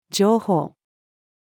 情報-female.mp3